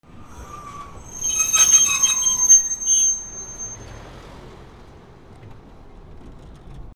路面電車ブレーキ